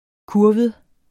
Udtale [ ˈkuɐ̯wəð ]